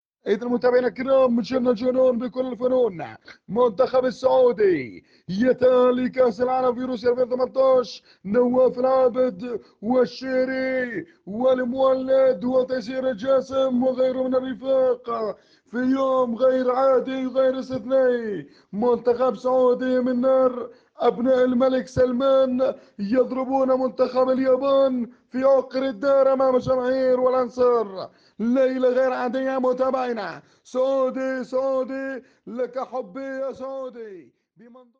تقليد عصام الشوالى للمنتخب السعودى